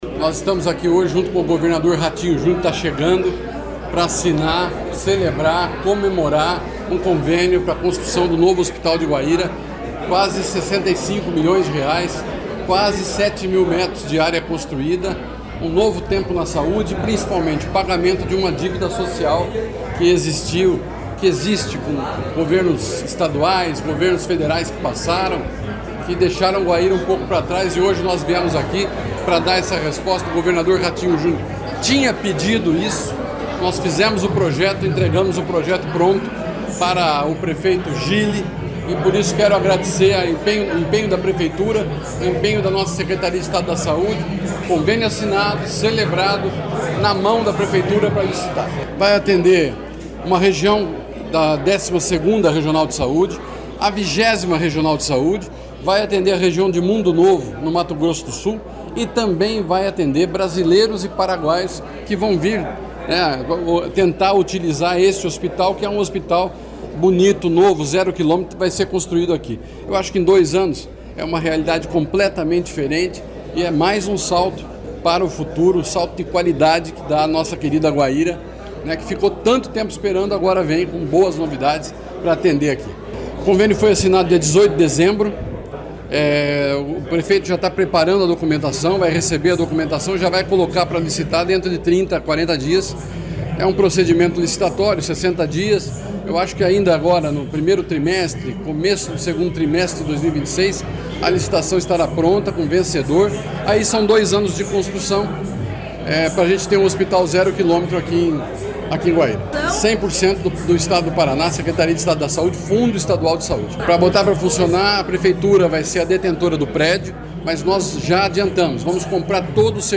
Sonora do secretário da Saúde, Beto Preto, sobre a construção de um novo hospital em Guaíra